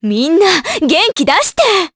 Juno-Vox_Skill2_jp.wav